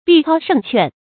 必操胜券 bì cāo shèng quàn
必操胜券发音
成语正音 券，不能读作“juàn”。